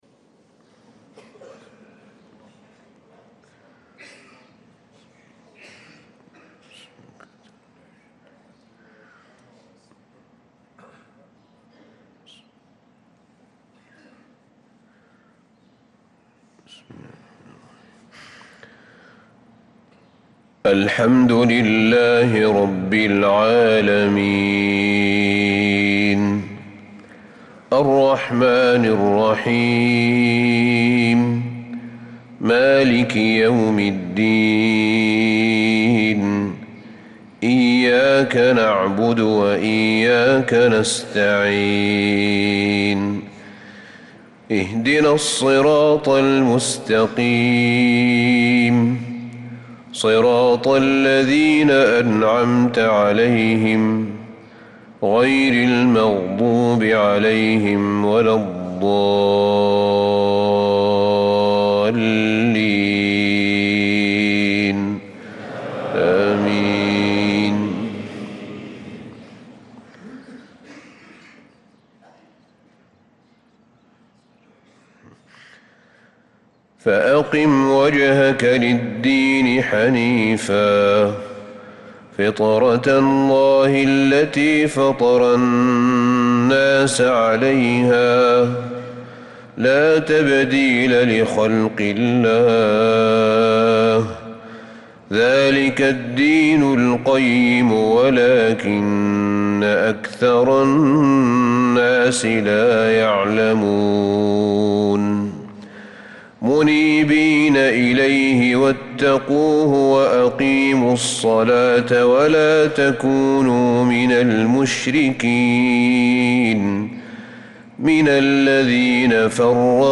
صلاة الفجر للقارئ أحمد بن طالب حميد 14 رجب 1446 هـ
تِلَاوَات الْحَرَمَيْن .